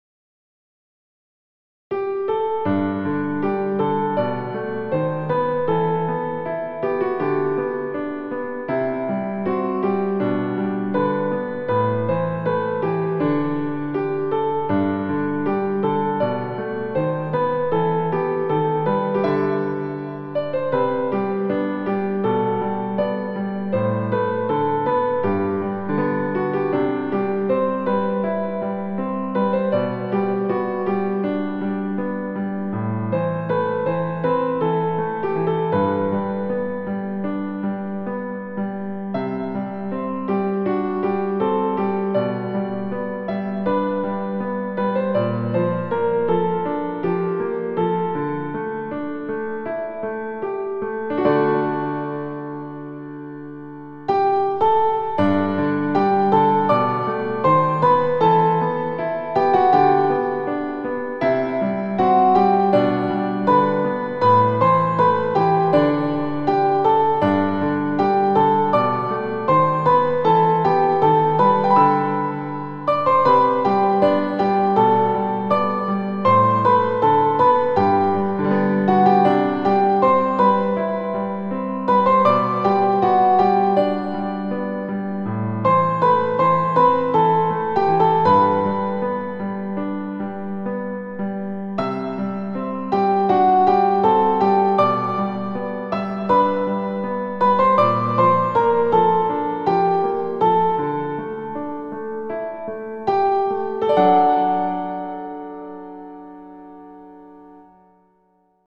伴奏はタイトルの通り分散和音(アルペジオ)で構成されています。
17小節目からメロディをオクターブの重音にしています。
Moderatoと表記している速度は、MP3/MIDIで四分音符＝80にしています。